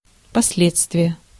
Ääntäminen
IPA: /pɐˈslʲet͡stvʲɪjə/